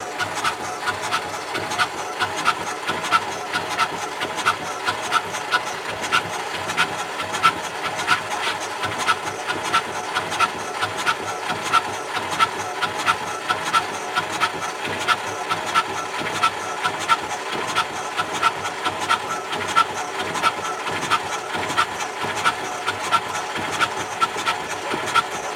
Airplane Windshield Wipers In Snow, Looped